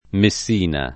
Messina [ me SS& na ]